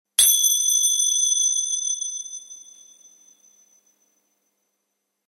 Golpe de crótalos con vibrato
vibrar
percusión
golpe